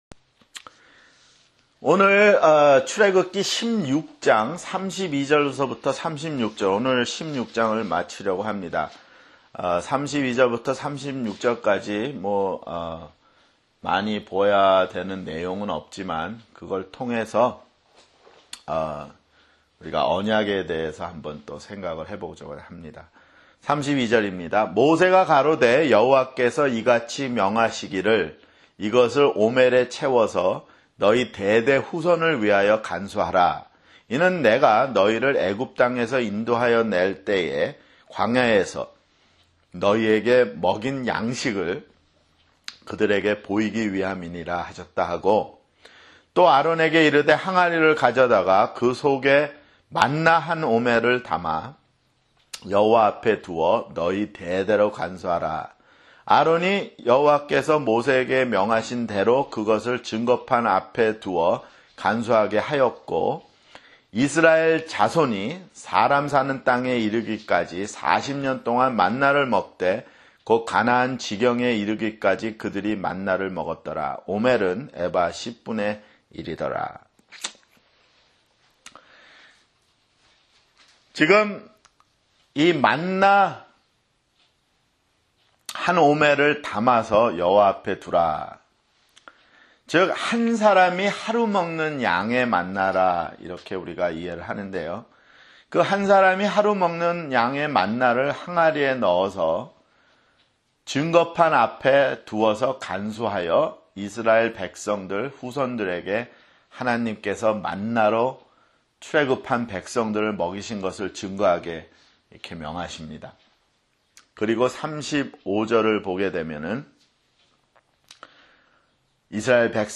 [성경공부] 출애굽기 (43)